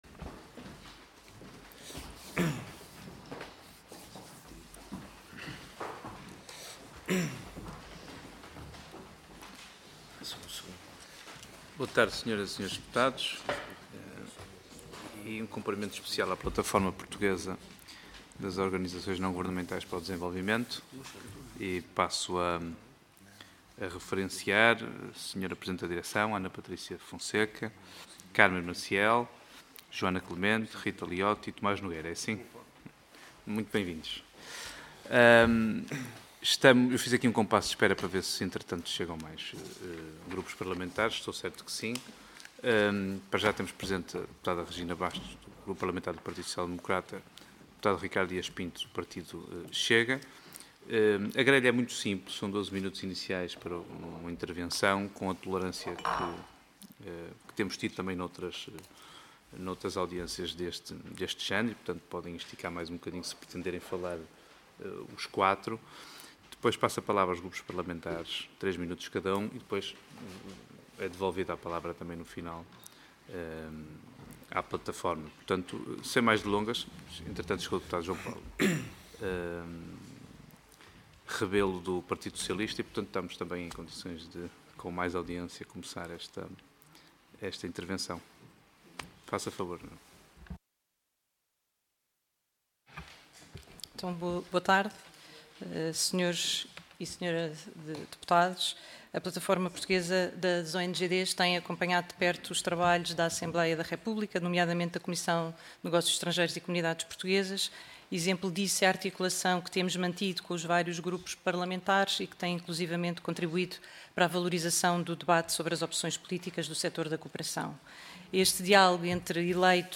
Comissão de Negócios Estrangeiros e Comunidades Portuguesas Audiência Parlamentar